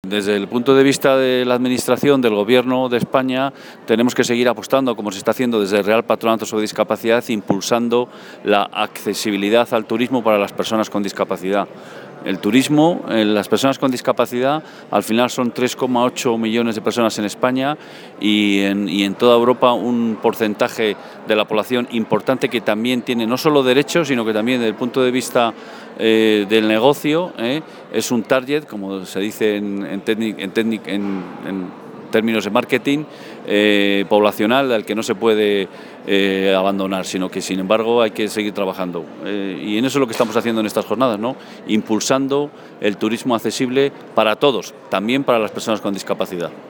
Por su parte, el director general de Políticas de Apoyo a la Discapacidad, Ignacio Tremiño, también participante en la jornada,